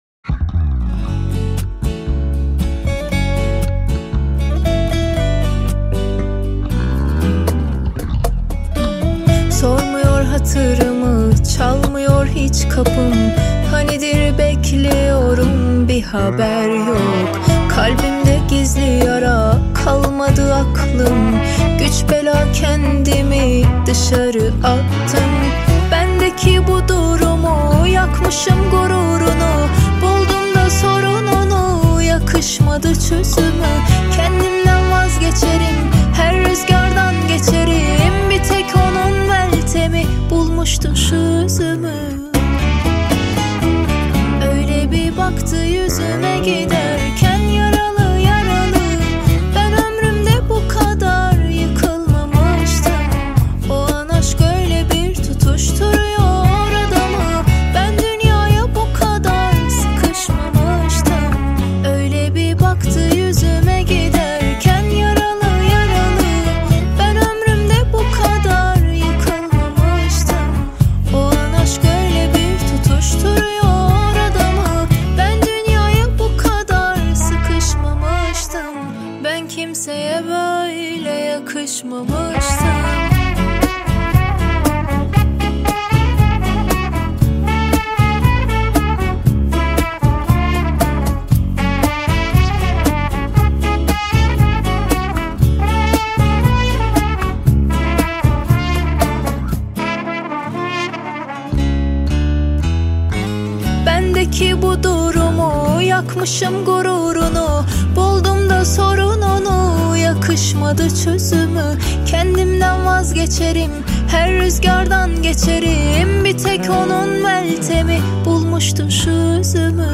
Турецкие песни